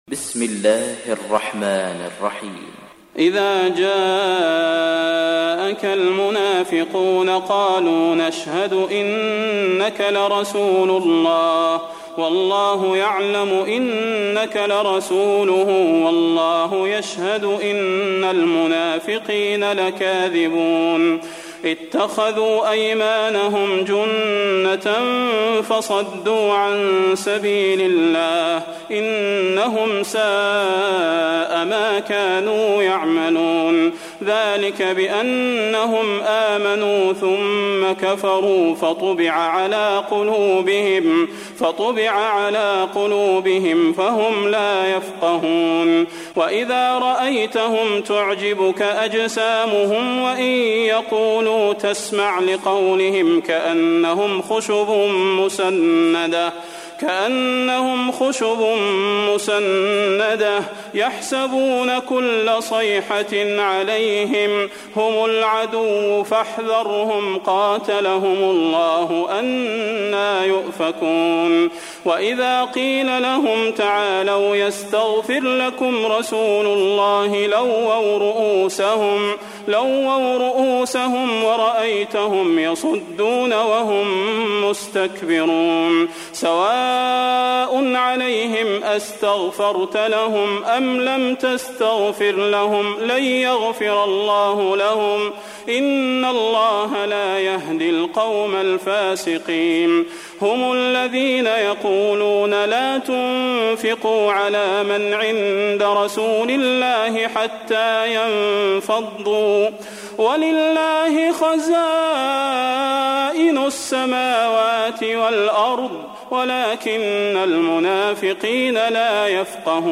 صلاح بن محمد البدير المصحف المرتل - حفص عن عاصم - المنافقون